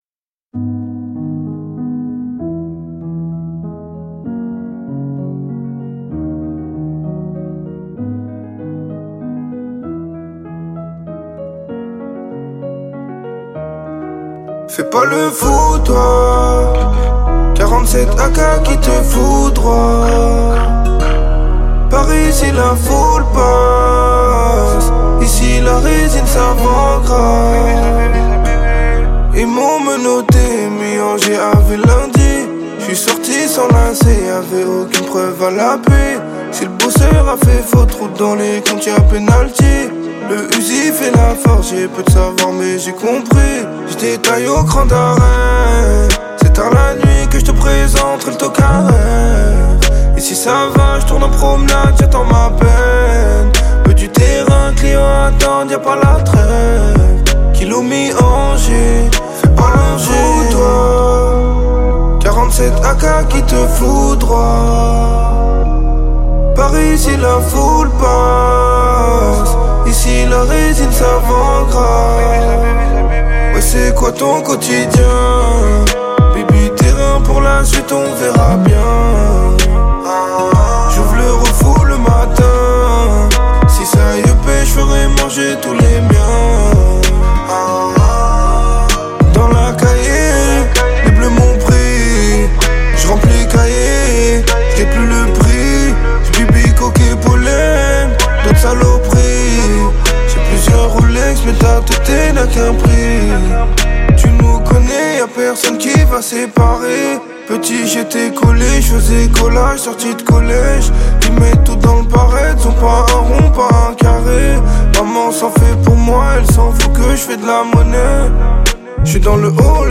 40/100 Genres : french rap, pop urbaine Télécharger